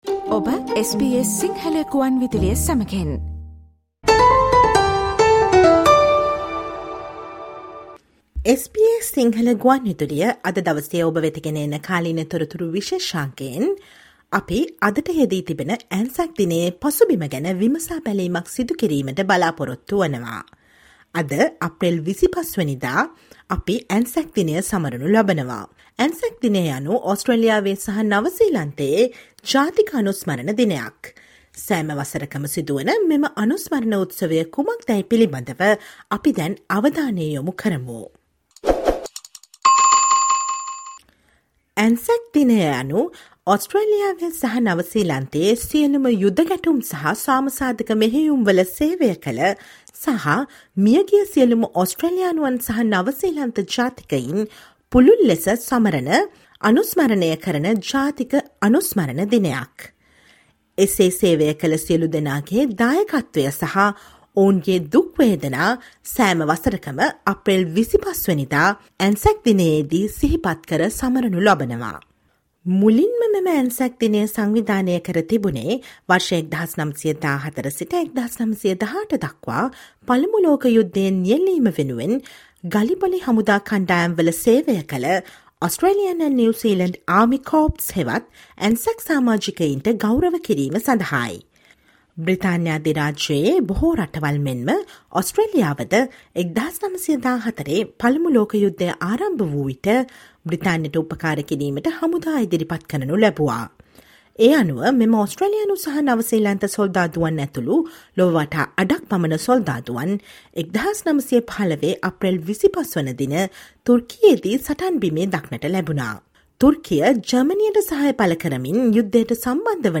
ANZAC is a national day of remembrance in Australia and New Zealand. Let’s look into this memorial celebration that happens every year by listening to this SBS Sinhala radio current affair feature on the background of ANZAC day.